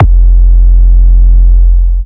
Index of /Antidote Advent/Drums - 808 Kicks
808 Kicks 07 F.wav